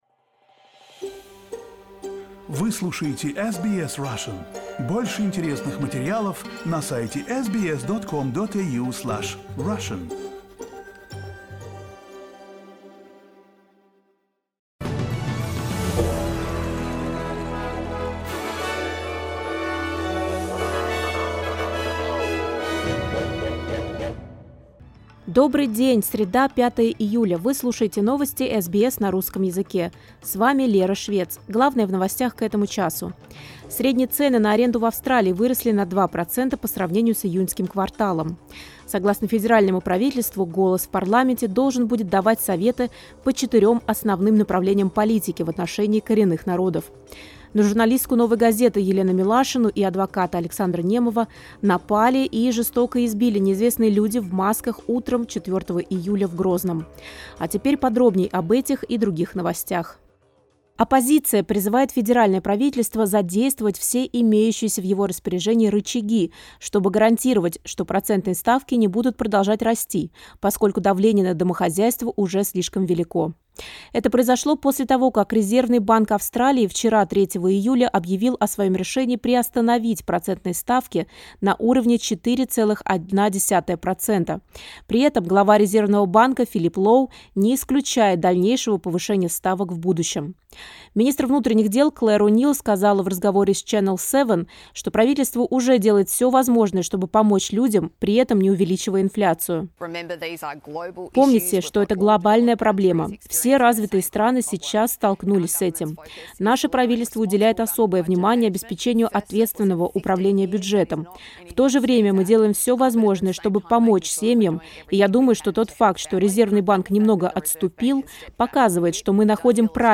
SBS news in Russian — 05.07.2023